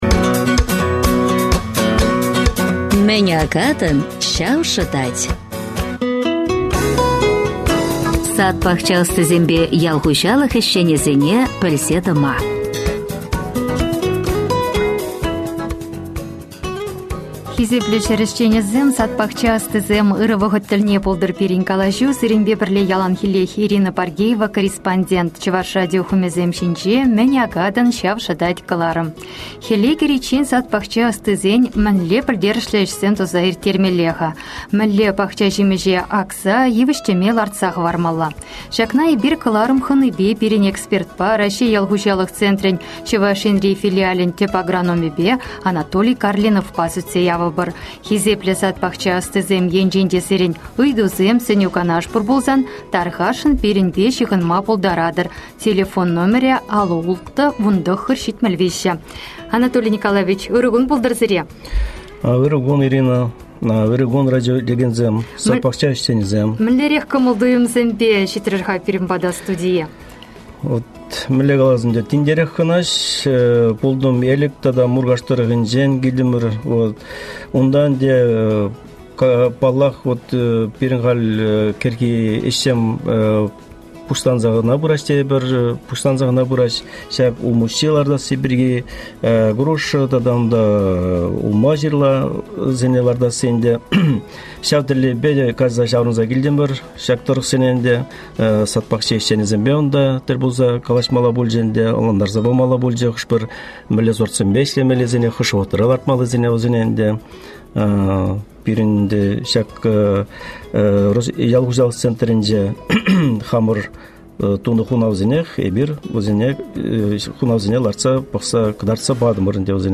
Прямой эфир